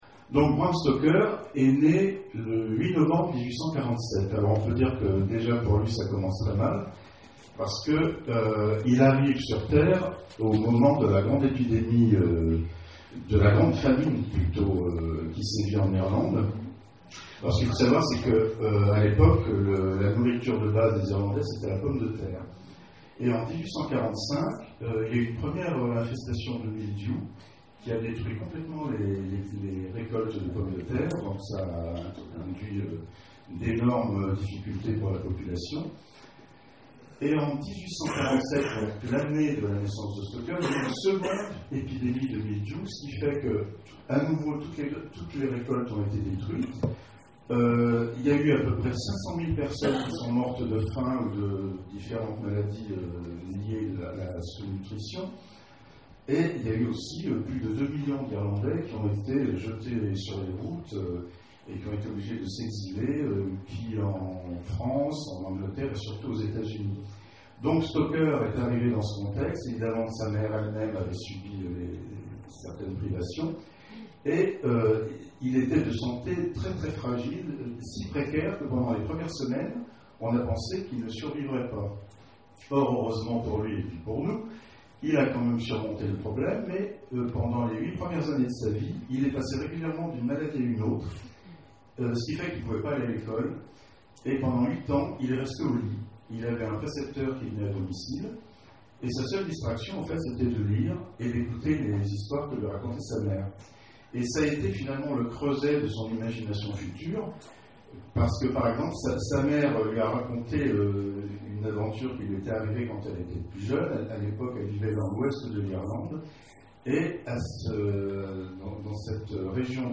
Salon du roman populaire d'Elven : conférence De Bram Stoker à Stephenie Meyer : création et évolution (involution) des codes du vampire
Conférence